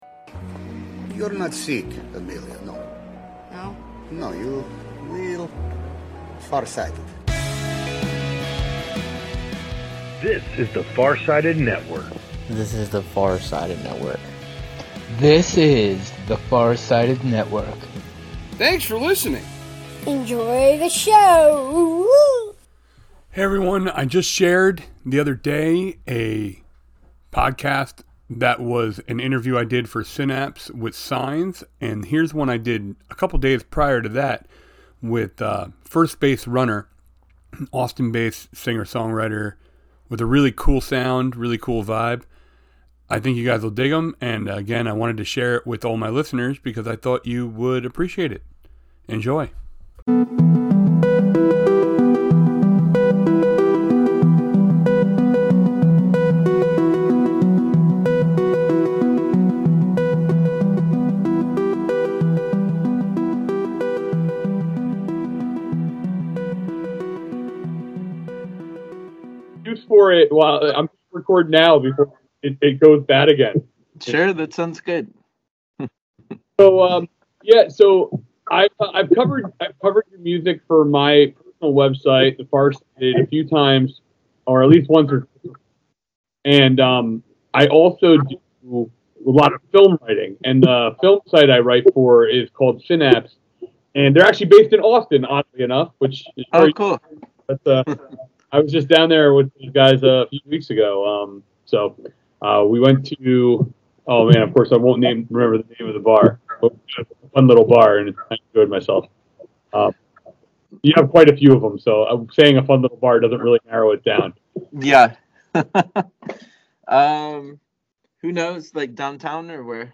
The rebranded pod features raw and (mostly) unedited interviews, both new and not-so-new.